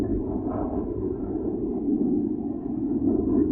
thruster.ogg